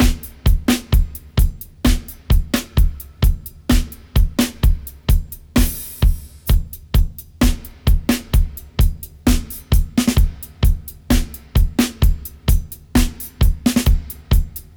129-FX-01.wav